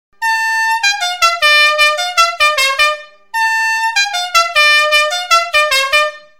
Fisa avertisseur Metal 24V - Lambada | 146129C-24V